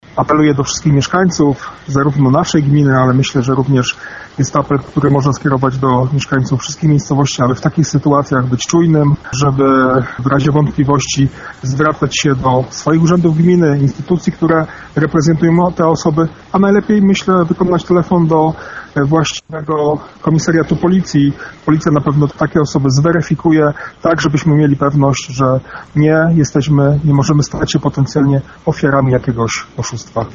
W przypadku wątpliwości najlepiej skontaktować się z miejscową policją. Mówi wicewójt gminy Kozy, Marcin Lasek.